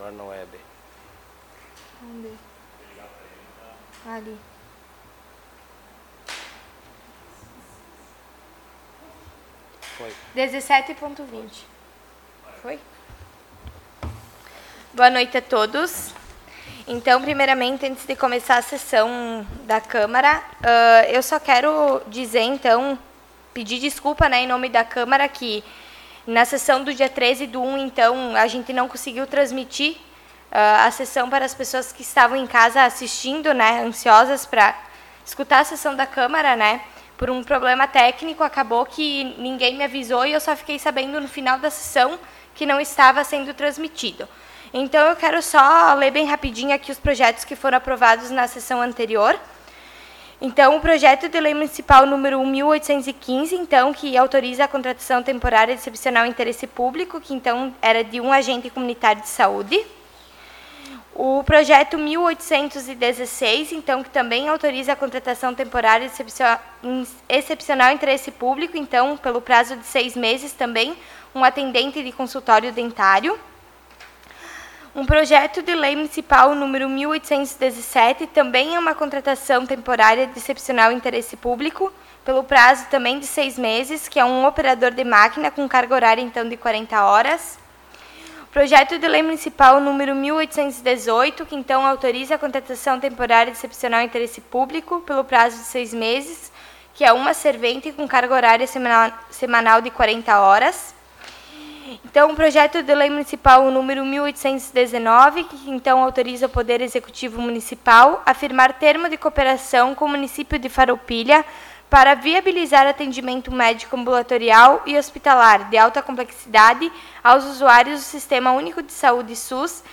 02° Sessão Ordinária de 2026
Áudio da Sessão